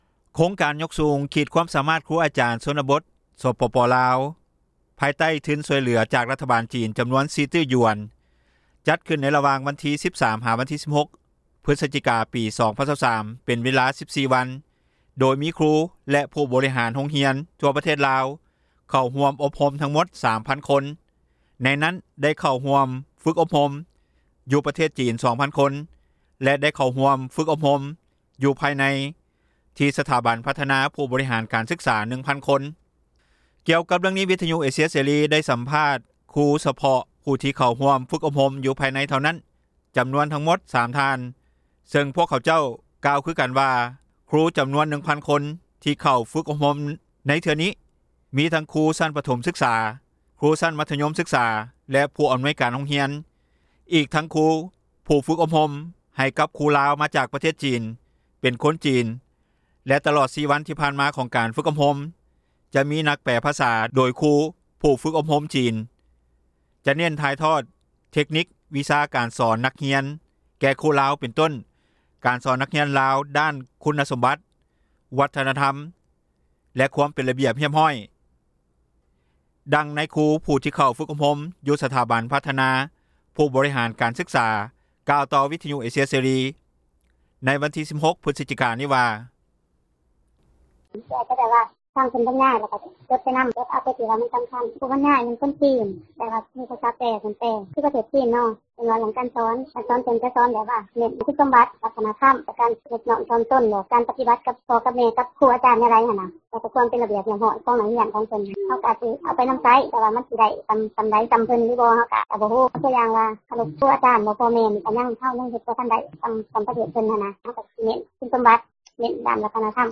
ກ່ຽວກັບເຣື່ອງນີ້ ວິທຍຸເອເຊັຽ ເສຣີ ໄດ້ສັມພາດຄຣູ ສະເພາະຜູ້ທີ່ເຂົ້າຮ່ວມຝຶກອົບຮົມ ຢູ່ພາຍໃນເທົ່ານັ້ນຈຳນວນທັງໝົດ 3 ທ່ານ ເຊິ່ງພວກເຂົາເຈົ້າ ກ່າວຄືກັນວ່າ ຄຣູຈຳນວນ 1,000 ຄົນ ການໂຮງຮຽນອີກທັງຄຣູ ຜູ້ຝຶກອົບຮົມ ໃຫ້ກັບຄຣູລາວ ມາຈາກປະເທດຈີນ ເປັນຄົນຈີນ ແລະຕລອດ 4 ວັນ ທີ່ຜ່ານຂອງການຝຶກອົບຮົມ ຈະມີນາຍແປພາສາ ໂດຍຄຣູຝຶກອົບຮົມຈີນ ຈະເໜັ້ນຖ່າຍທອດເຕັກນິກ ວິທີການສອນນັກຮຽນ ແກ່ຄຣູລາວ ເປັນຕົ້ນການສອນນັກຮຽນລາວ ດ້ານຄຸນນະສົມບັດ, ວັທນະທັມ ແລະ ຄວາມເປັນລະບຽບຮຽບຮ້ອຍ.